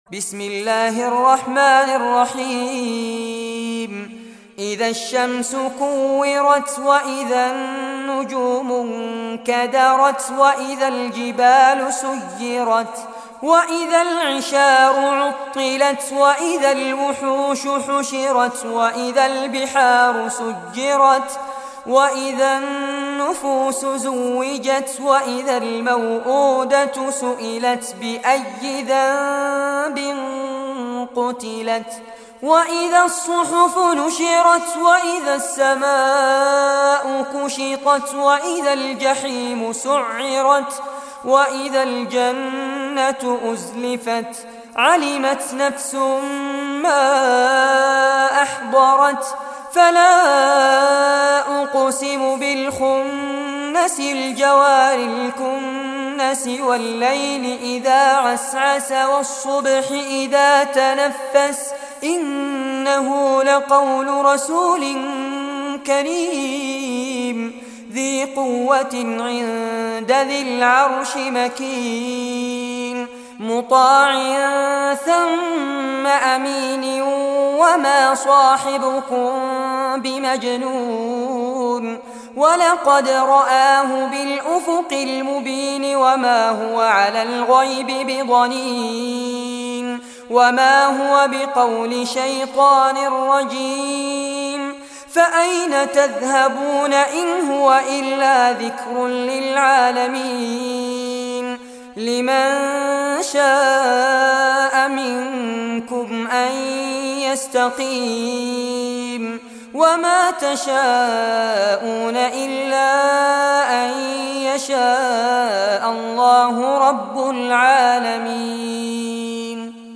Surah At-Takwir Recitation by Fares Abbad
Surah At-Takwir, listen or play online mp3 tilawat / recitation in Arabic in the beautiful voice of Sheikh Fares Abbad.